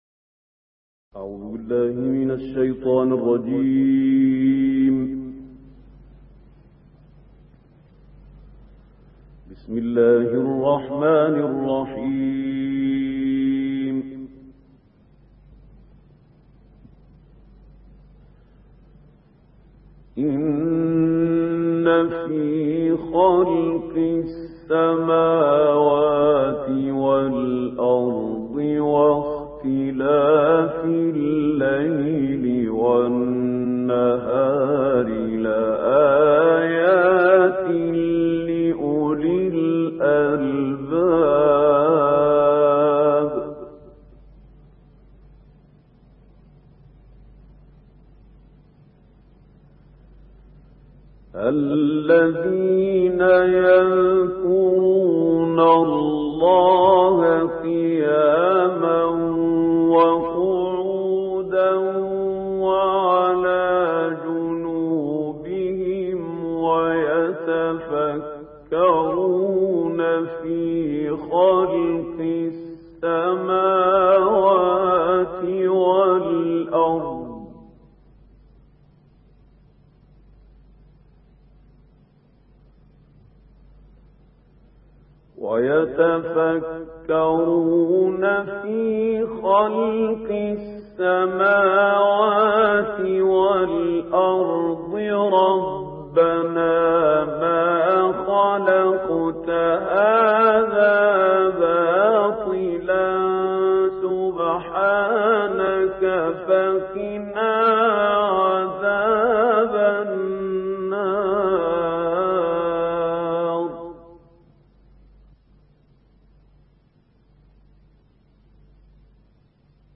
تلاوت مجلسی کوتاه
تلاوت کوتاه مجلسی خلیل الحصری از آیات 190 تا 194 سوره آل عمران به مدت 4 دقیقه و 59 ثانیه